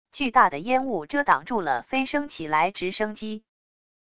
巨大的烟雾遮挡住了飞升起来直升机wav音频